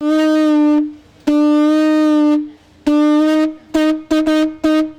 Instrumento aborigen: caracola
percusión
caracola